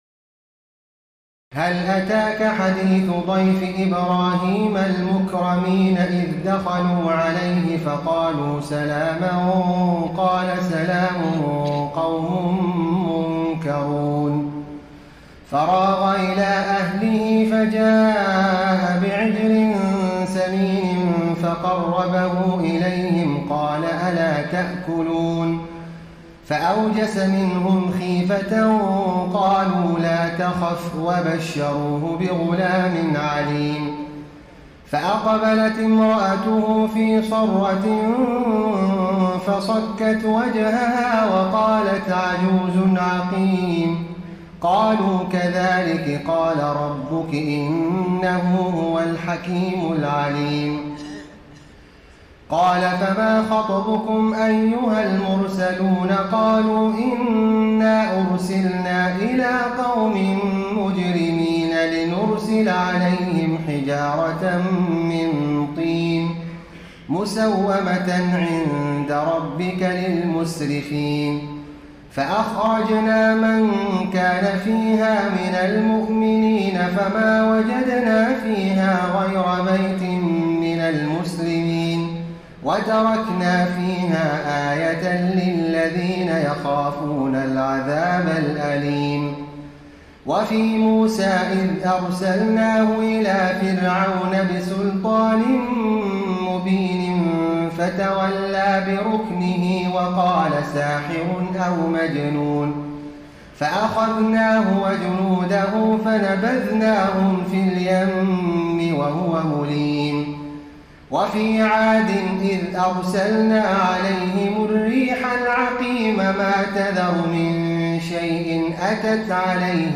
تراويح ليلة 26 رمضان 1433هـ من سور الذاريات(24-60) و الطور و النجم و القمر Taraweeh 26 st night Ramadan 1433H from Surah Adh-Dhaariyat and At-Tur and An-Najm and Al-Qamar > تراويح الحرم النبوي عام 1433 🕌 > التراويح - تلاوات الحرمين